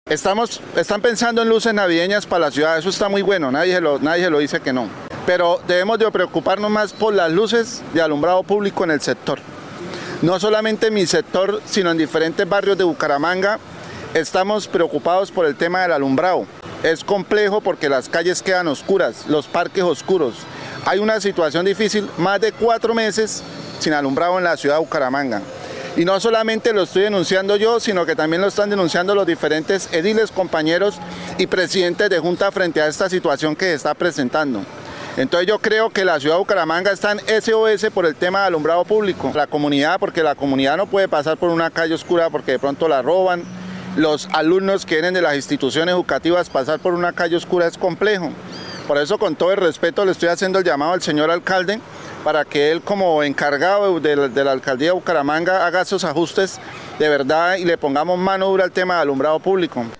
Jorge Leonardo León, edil comuna 4 de Bucaramanga